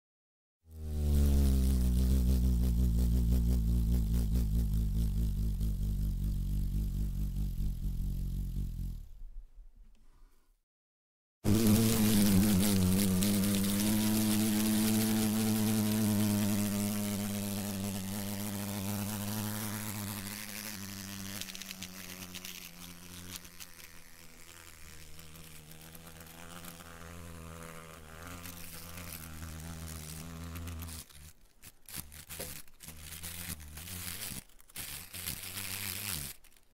Звуки шершня
Шершень с огромными крыльями